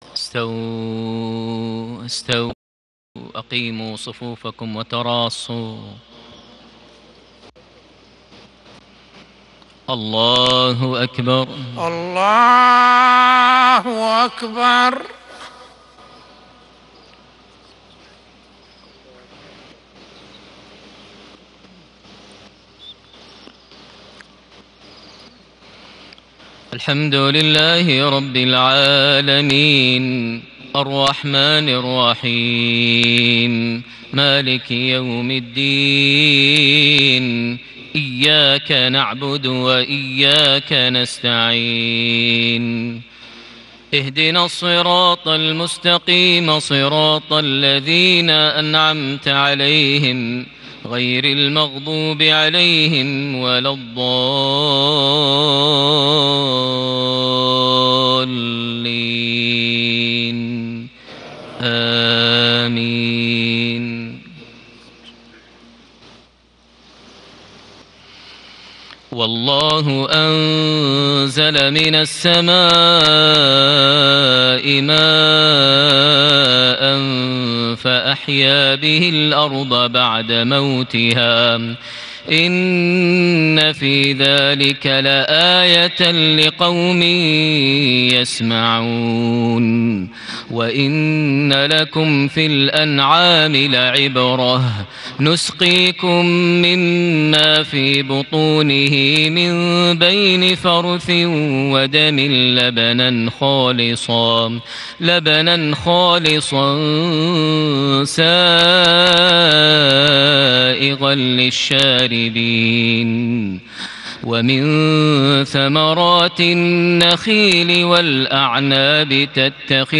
صلاة المغرب ٨ جماد الآخر ١٤٣٨هـ سورة النحل ٦٥-٧٢ > 1438 هـ > الفروض - تلاوات ماهر المعيقلي